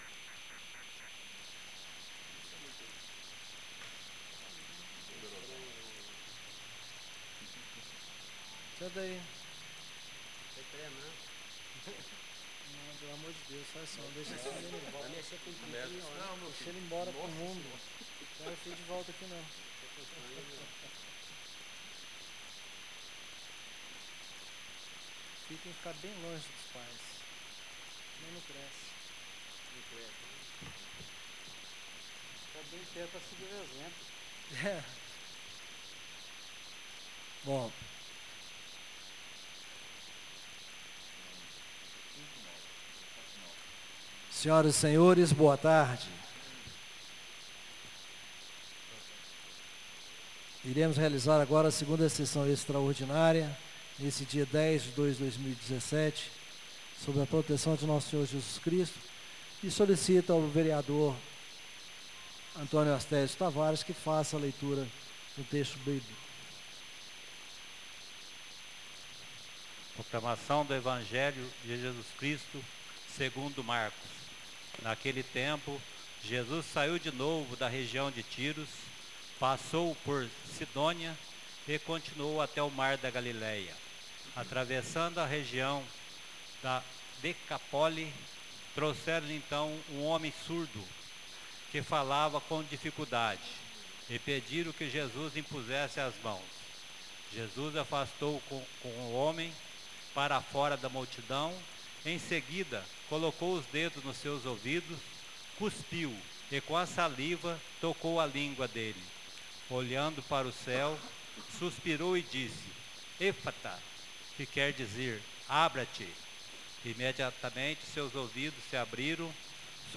Tipo de Sessão: Extraordinária